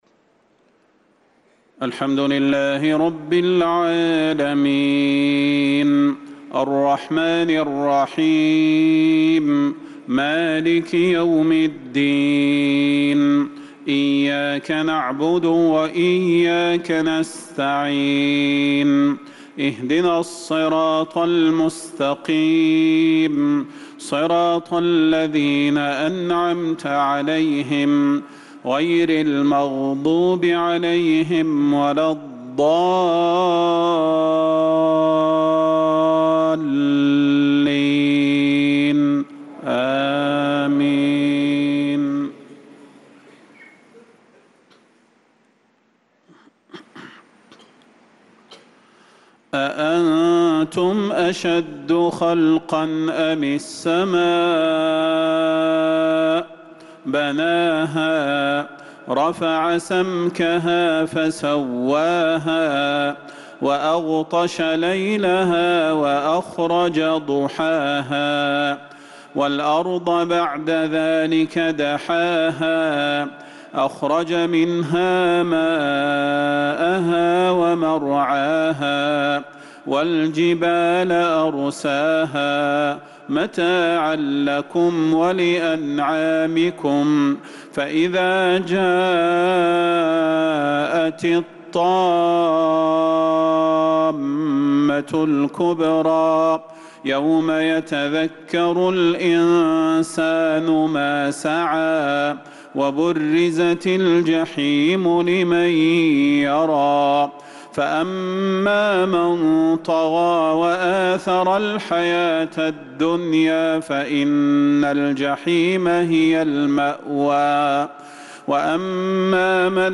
صلاة العشاء للقارئ صلاح البدير 29 ذو الحجة 1445 هـ
تِلَاوَات الْحَرَمَيْن .